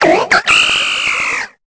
Cri de Sucreine dans Pokémon Épée et Bouclier.